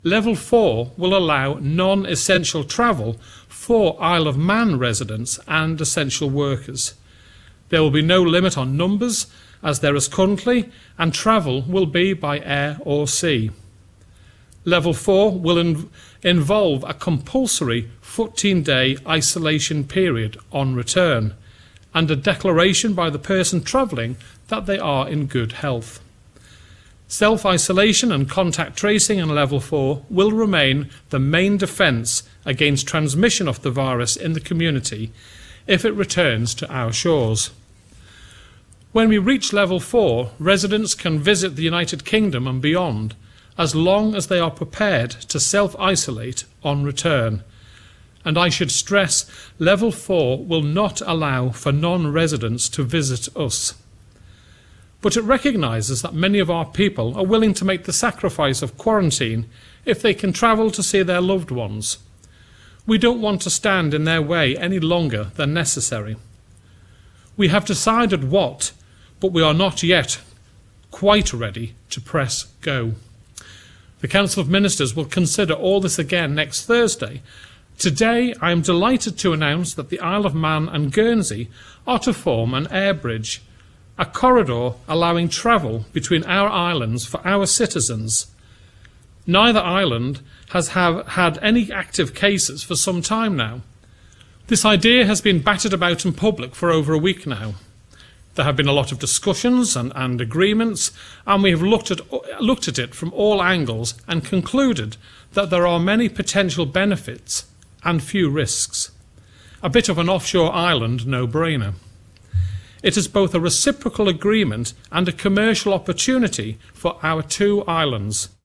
Speaking at today's government media briefing, the chief minister says this link will be reviewed, and it could even be expanded to other jurisdictions if it works.